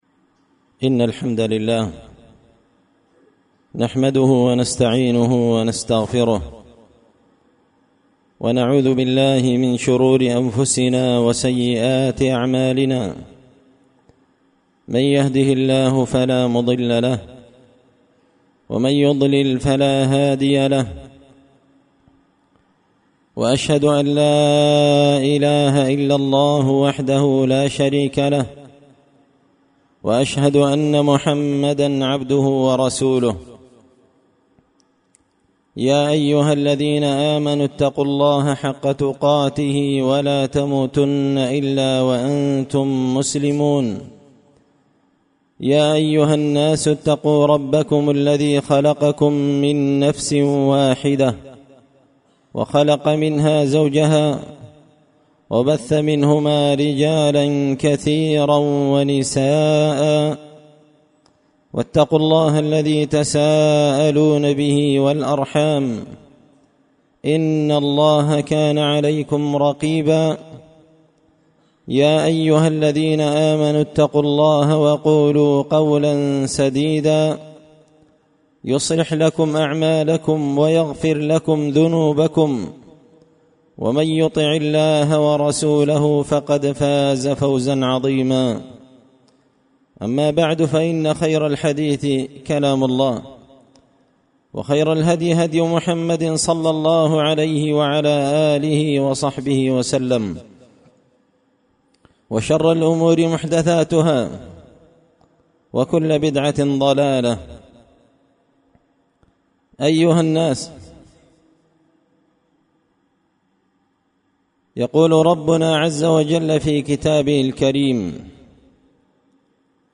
خطبة جمعة بعنوان – هل يحب النبي من لا يحتفل بمولده
دار الحديث بمسجد الفرقان ـ قشن ـ المهرة ـ اليمن